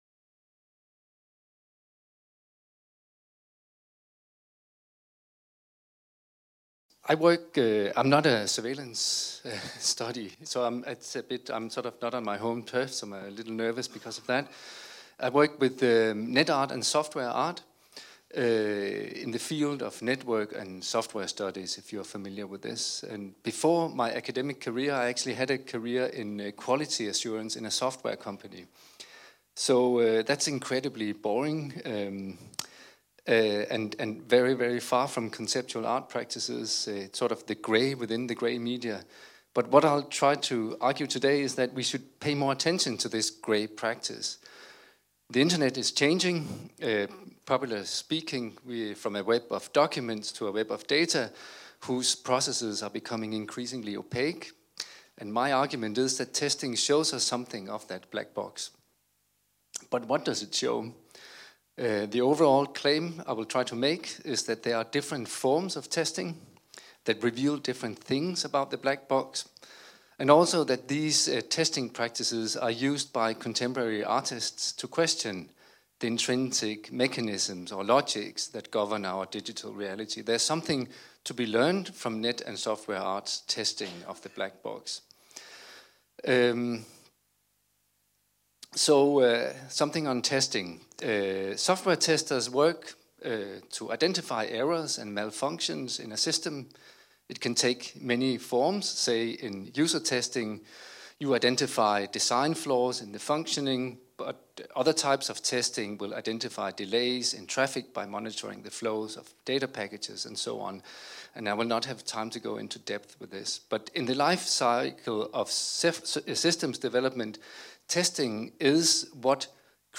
intervention